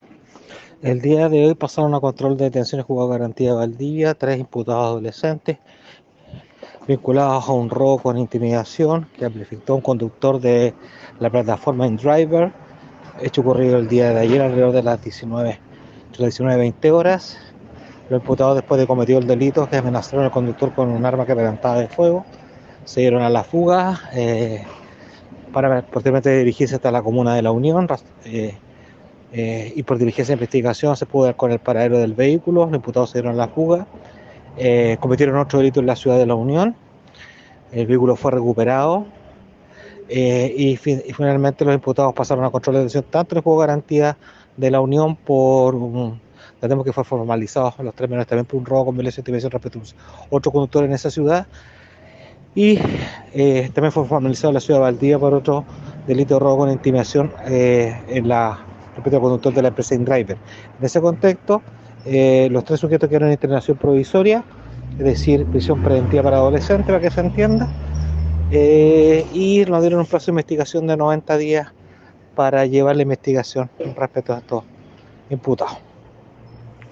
fiscal José Rivas sobre la formalización efectuada en Valdivia contra los tres imputados adolescentes.